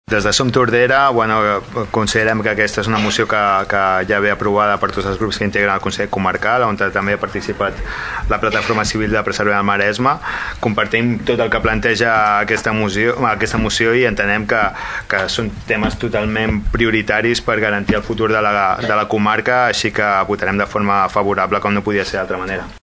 Salvador Giralt, regidor de Som Tordera, destaca que la mobilitat és un tema prioritari pel futur del Maresme.